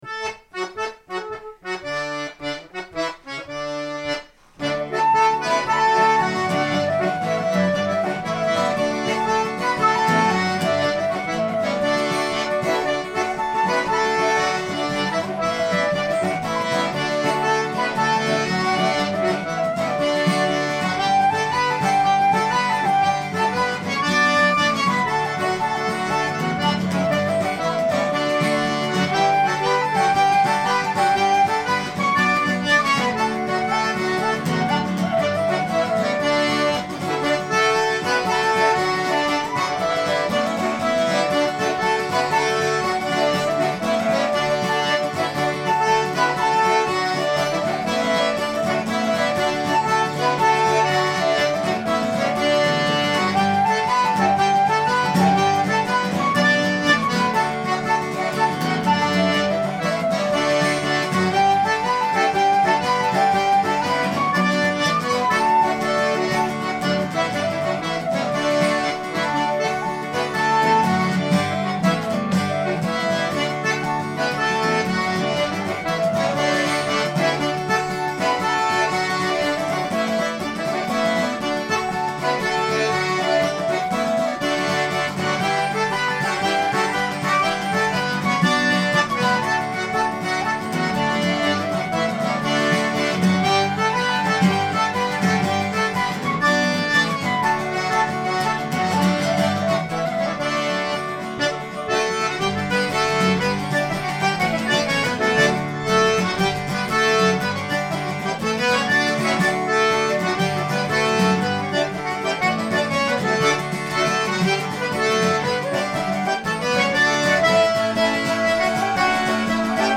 We are an acoustic duo and trio that plays for English barndances.
Great music, with a fresh yet traditional sound.